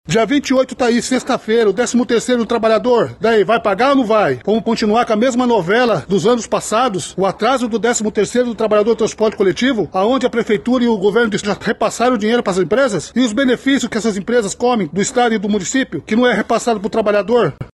Ainda segundo o líder, há preocupação com relação ao pagamento do 13º salário dos trabalhadores, além de ameaças constantes para que os funcionários não se manifestem.